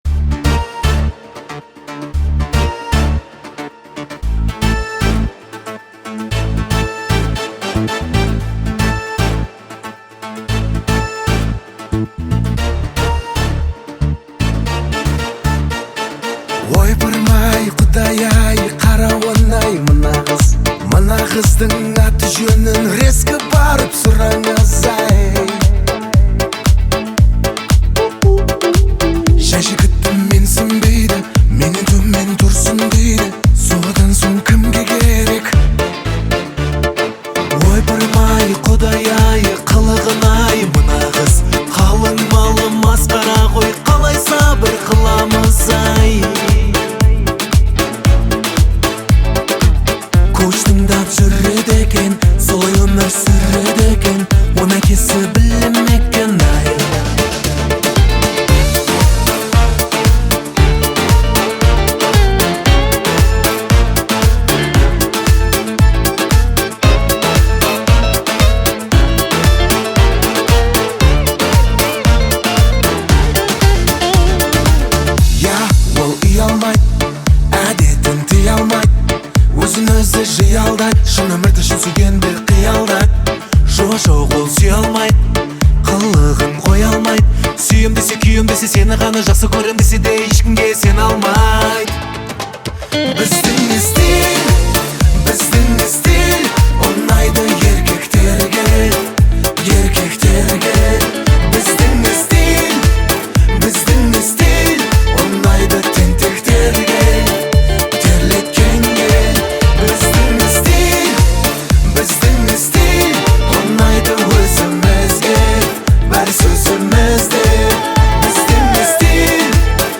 Качество: 320 kbps, stereo
Казахская музыка